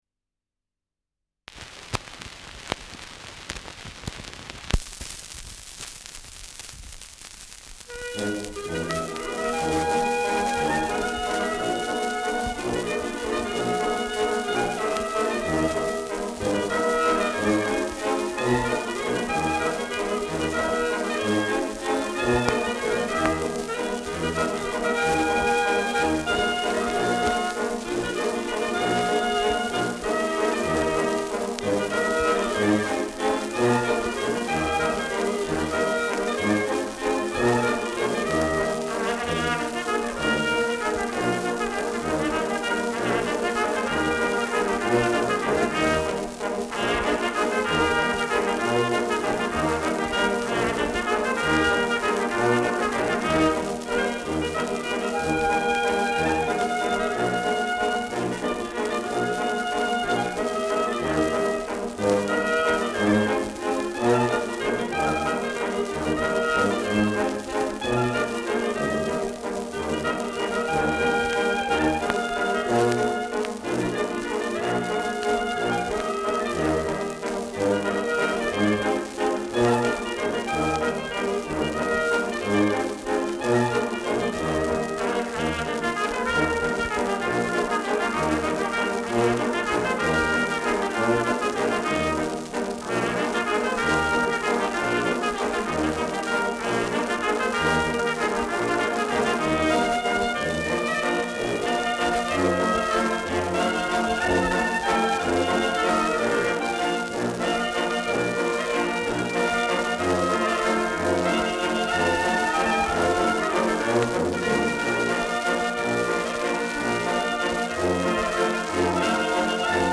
Schellackplatte
Stärkeres Grundrauschen : Vereinzelt leichtes Knacken
[München] (Aufnahmeort)